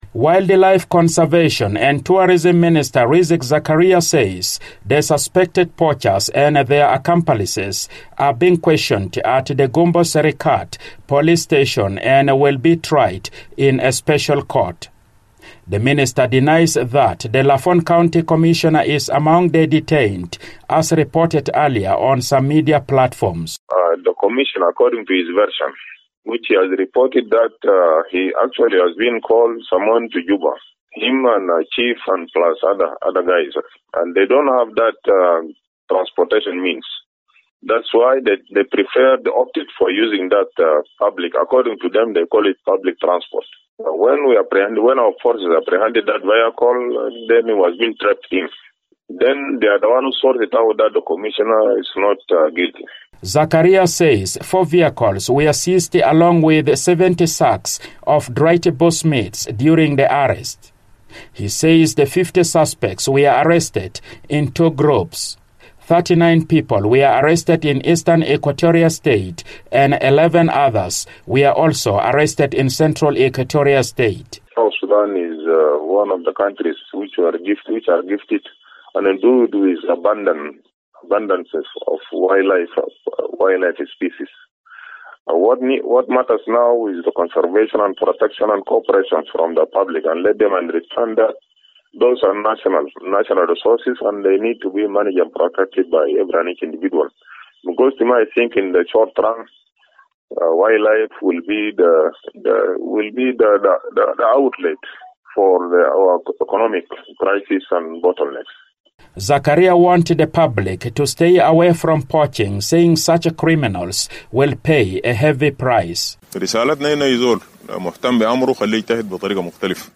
The South Sudan Ministry of Wildlife Conservation and Tourism has opened a police case against dozens of suspected poachers arrested late last week in Badingilo national park. The Wildlife, Conservation and Tourism Minister tells South Sudan in Focus in an exclusive interview that a wide-ranging investigation is underway into the practice of illegal poaching in South Sudan.